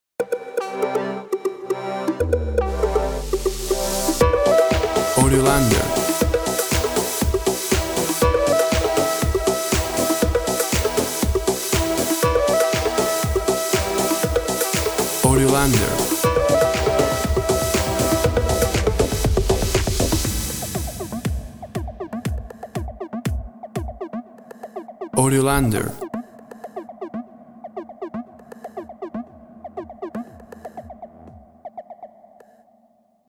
WAV Sample Rate 16-Bit Stereo, 44.1 kHz
Tempo (BPM) 120